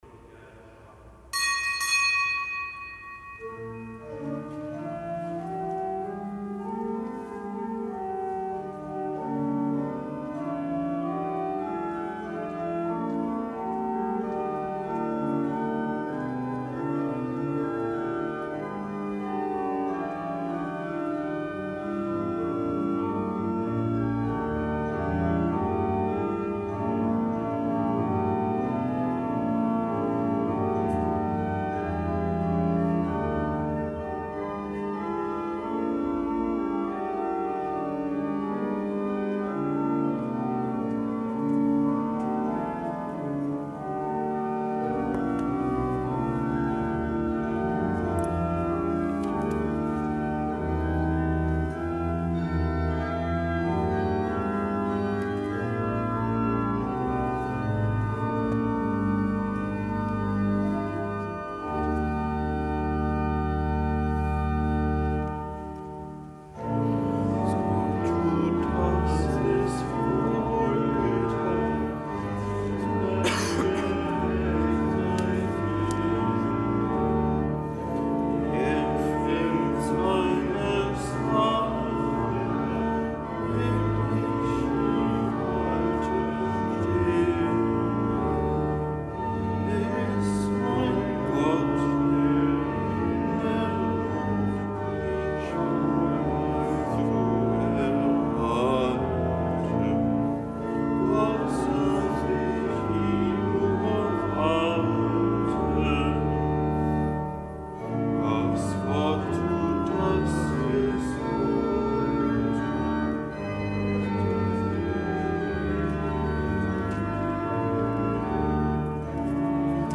Kapitelsmesse aus dem Kölner Dom am Dienstag der sechsten Woche im Jahreskreis. Zelebrant: Weihbischof Rolf Steinhäuser.